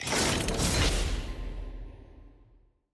sfx-loot-reroll-intro.ogg